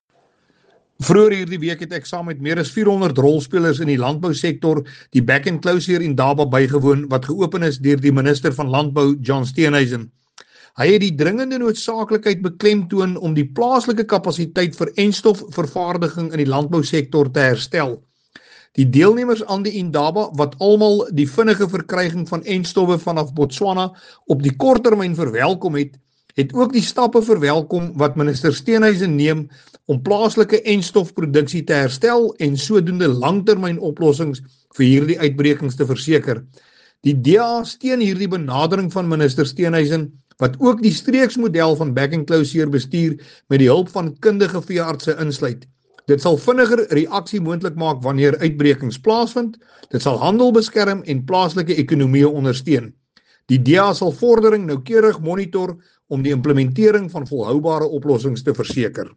Afrikaans soundbites by Willie Aucamp MP.
Afrikaans-Willie-Aucamp-2.mp3